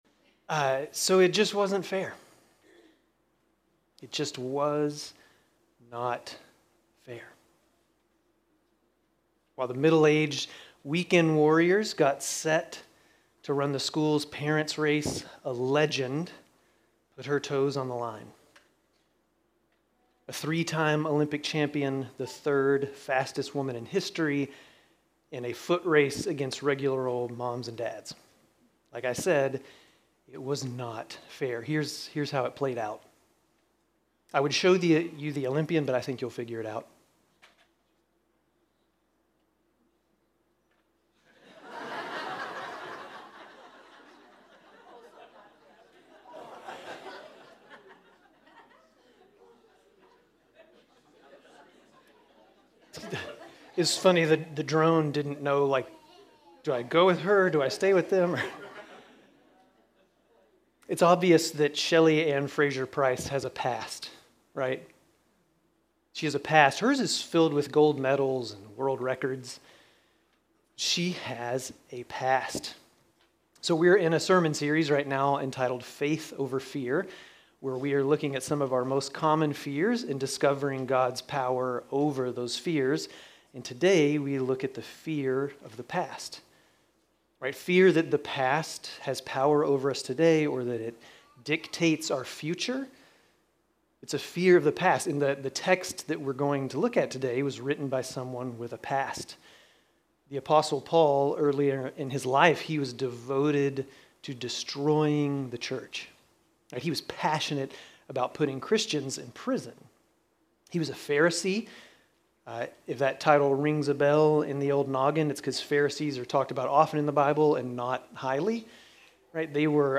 Grace Community Church Dover Campus Sermons 6_11 Dover Campus Jun 02 2025 | 00:28:32 1x 00:00 / 00:28:32 Subscribe Share RSS Feed Share Link Embed